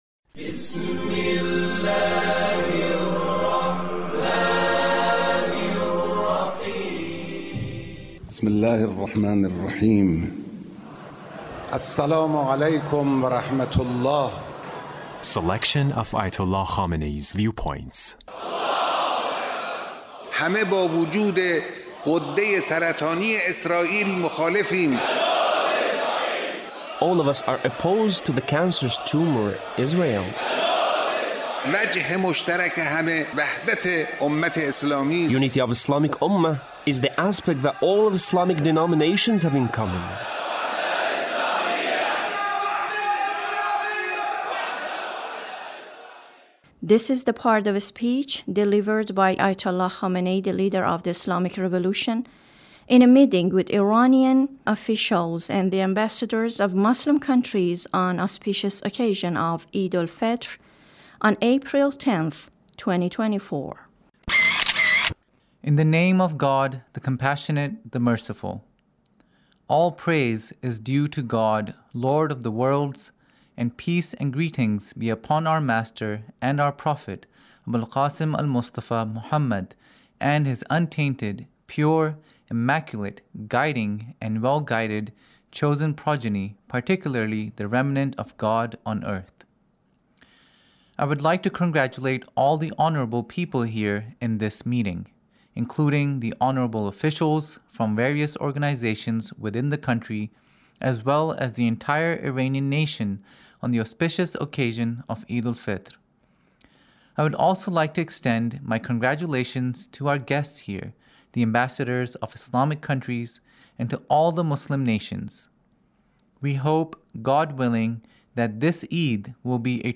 Leader's Speech in a Meeting with the Government Officials